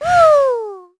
Estelle-Vox_blow_2.wav